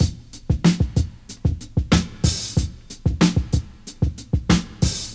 • 93 Bpm Fresh Drum Loop Sample E Key.wav
Free breakbeat - kick tuned to the E note.
93-bpm-fresh-drum-loop-sample-e-key-mED.wav